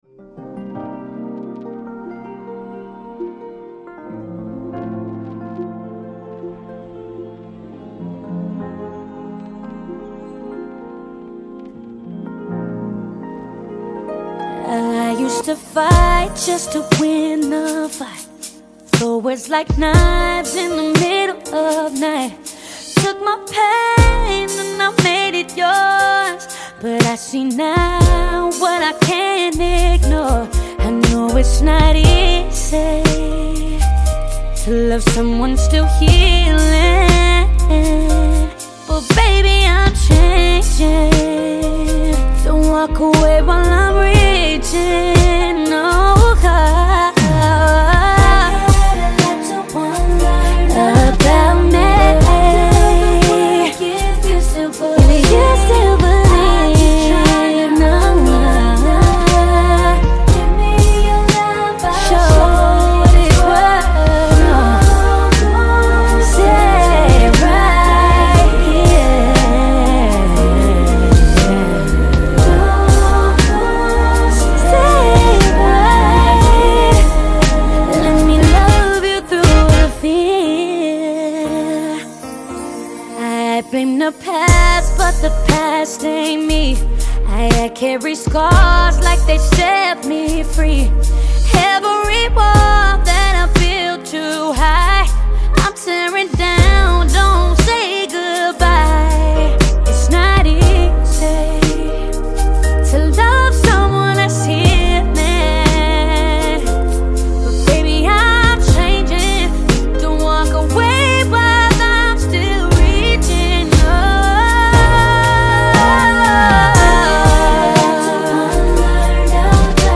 delivers a smooth and engaging tune
It keeps a steady rhythm that feels just right.